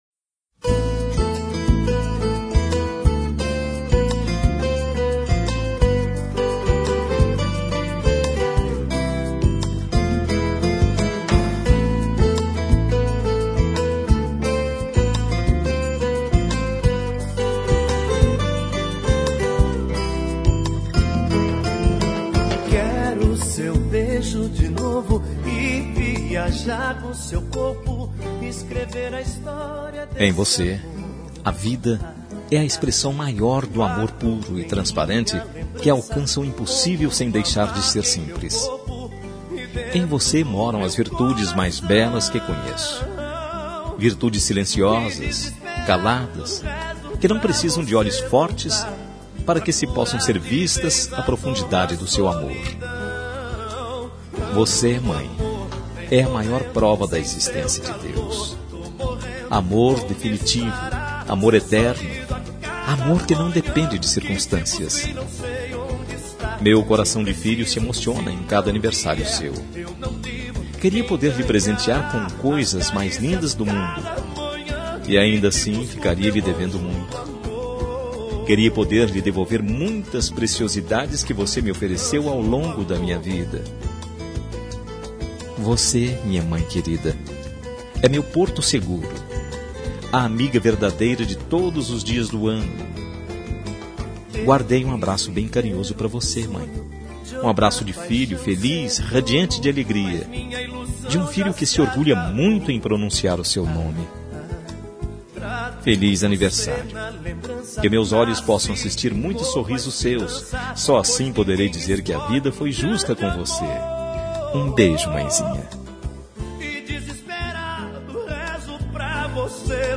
Telemensagem de Aniversário de Mãe – Voz Masculina – Cód: 1434